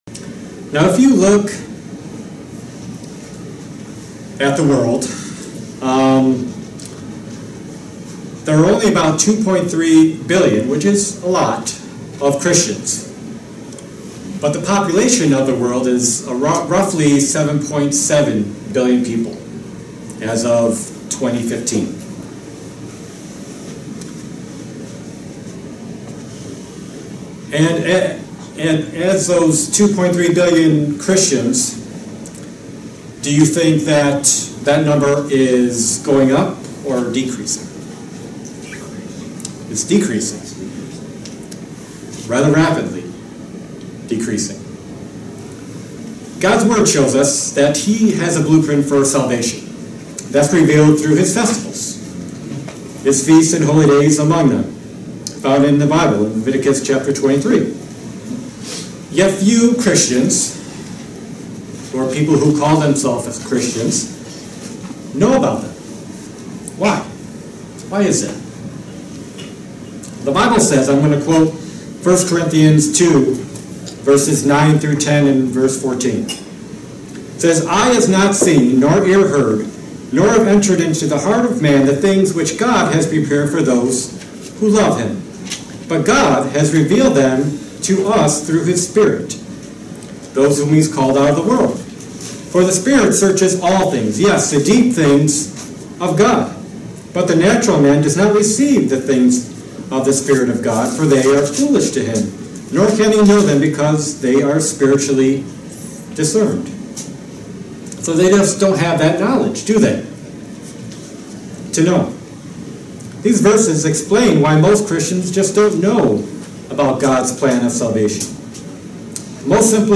This plan remains a mystery to most. In this sermon it will be explained.